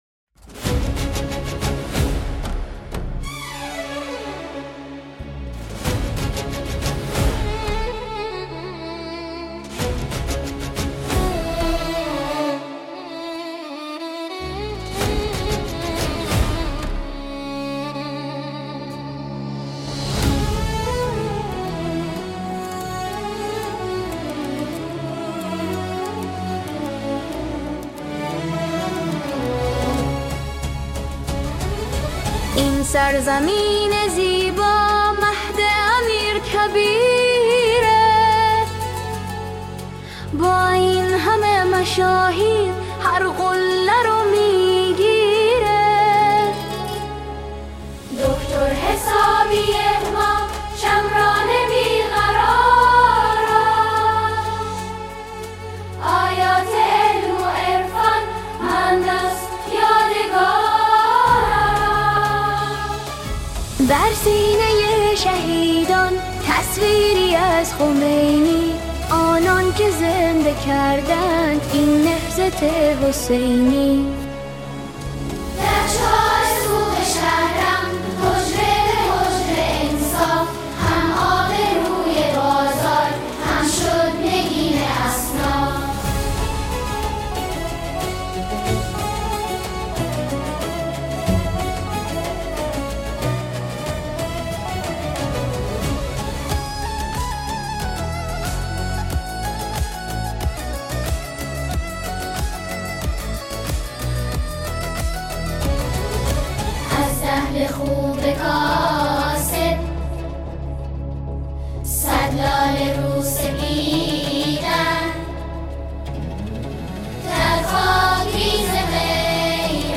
با اجرای دختران و پسران گروه سرود
ژانر: سرود